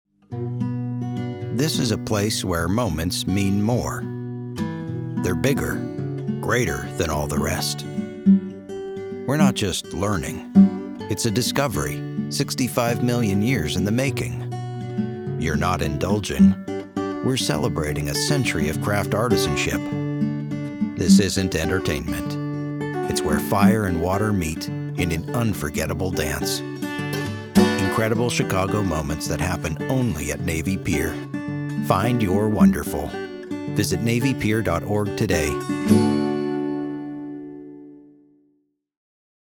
Navy Pier Radio Spot